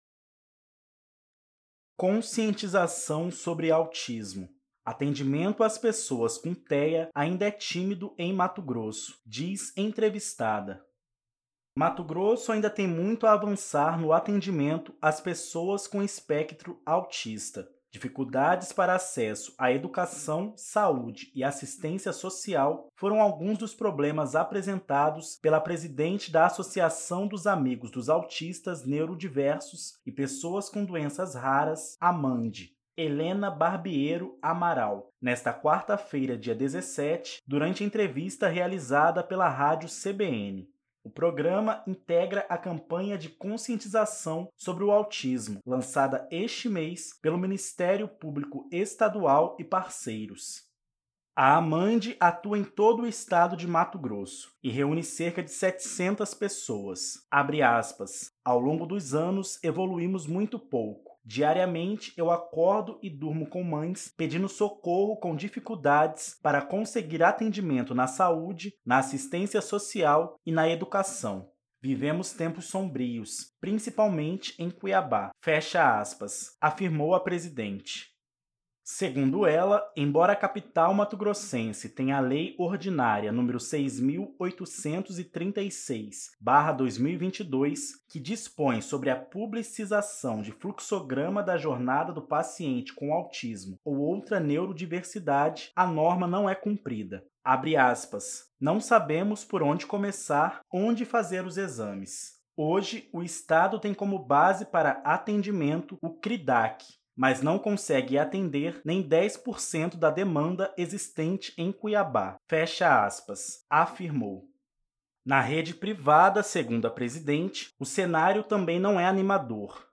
O programa integra a campanha de conscientização sobre o autismo lançada este mês pelo Ministério Público Estadual e parceiros.
Atendimento às pessoas com TEA ainda é tímido em MT, diz entrevistada.mp3